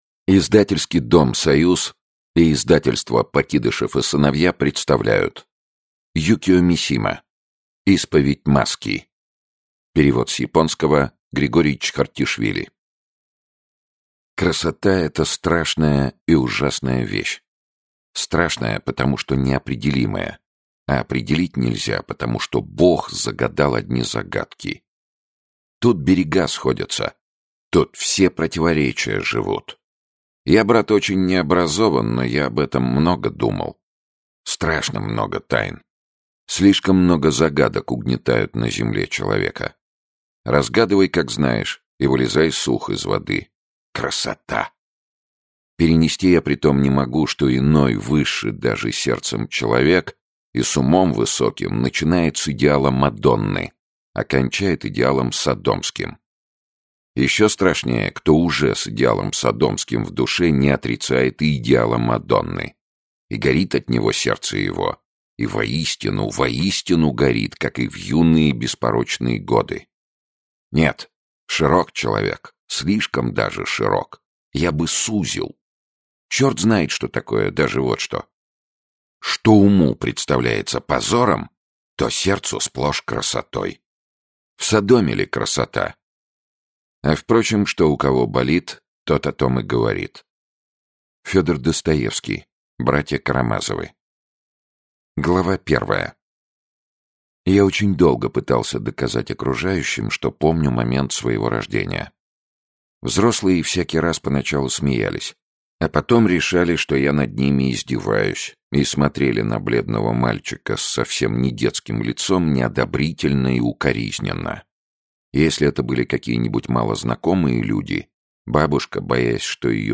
Аудиокнига Исповедь маски | Библиотека аудиокниг
Aудиокнига Исповедь маски Автор Юкио Мисима Читает аудиокнигу Сергей Чонишвили.